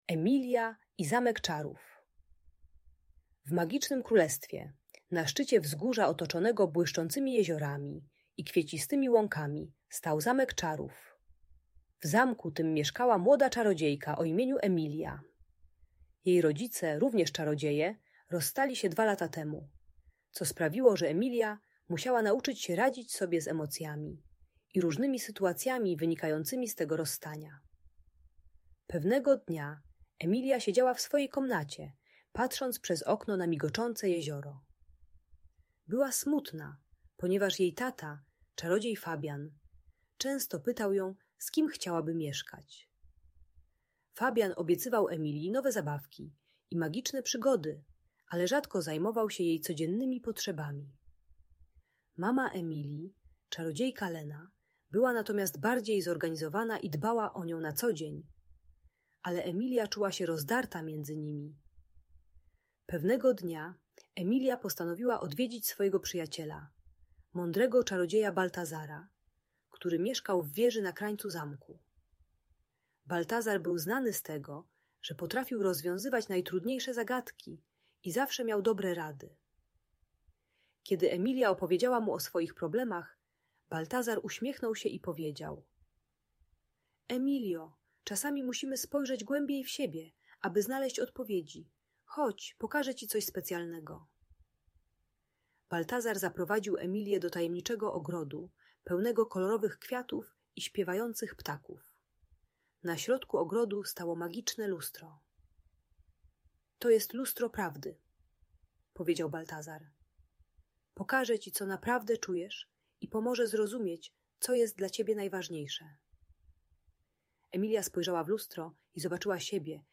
Emilia i Zamek Czarów - Audiobajka